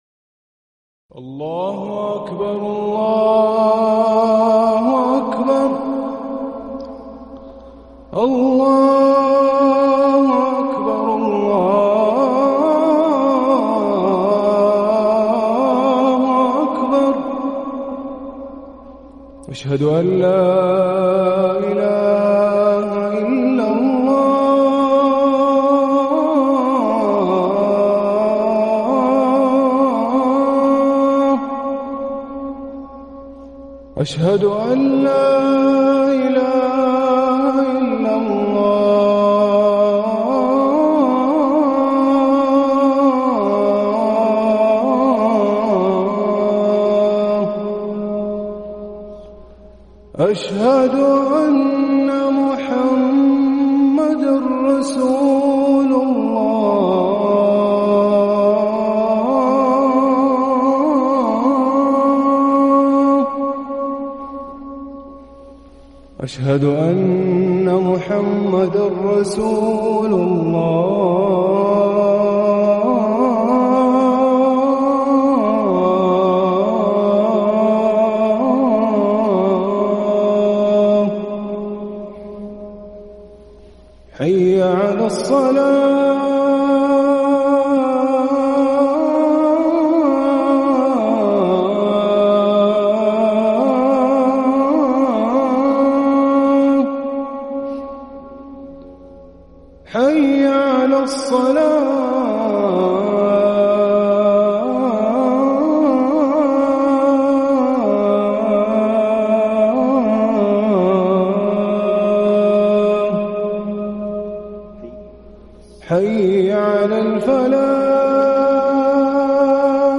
اذان المغرب للمؤذن هاشم السقاف الخميس 3 محرم 1443هـ > ١٤٤٣ 🕋 > ركن الأذان 🕋 > المزيد - تلاوات الحرمين